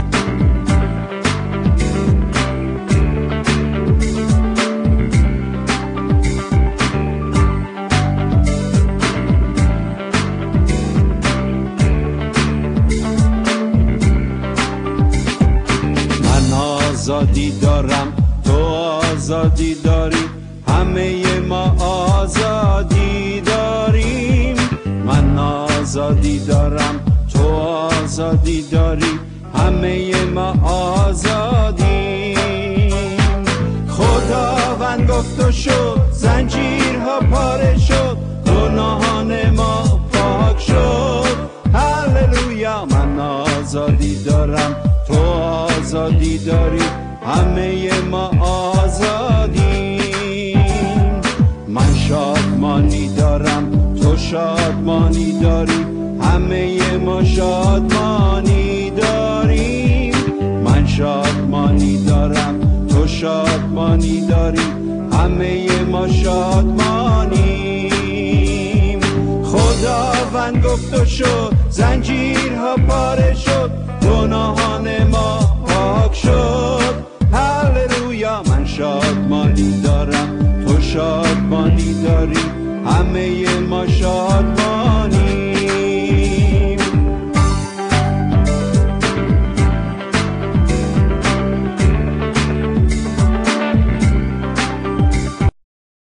Farsi Worship Christian Music